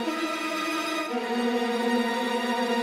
Index of /musicradar/gangster-sting-samples/85bpm Loops
GS_Viols_85-EB.wav